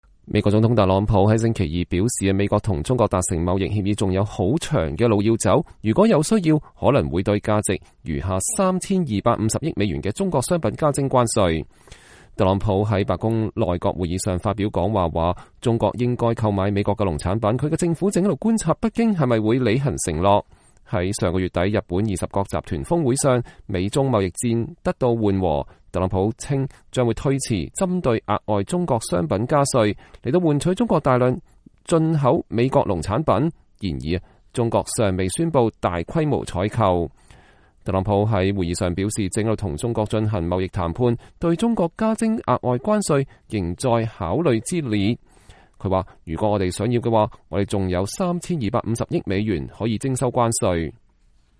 美國總統特朗普在華盛頓白宮內閣會議上發表講話（2019年7月16日）。